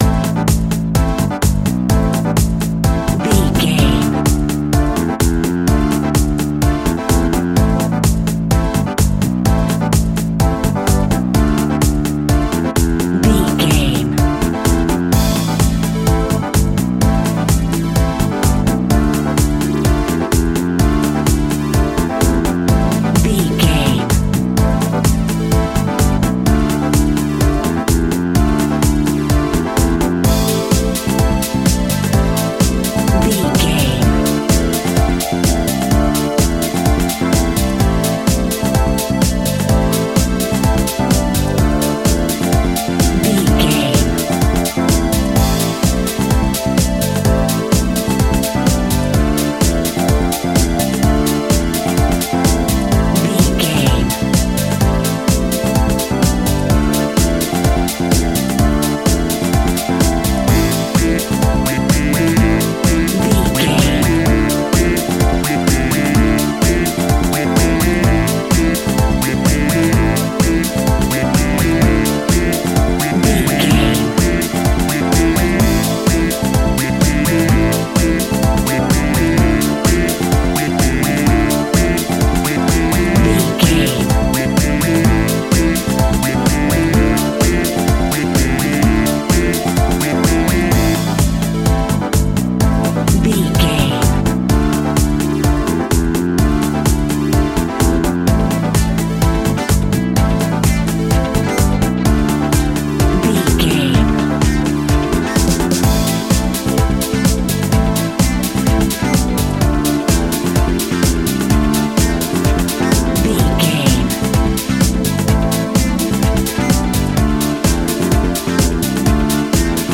Aeolian/Minor
groovy
uplifting
energetic
drum machine
synthesiser
funky house
nu disco
upbeat
funky guitar
clavinet
synth bass
horns